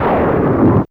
Clap1.wav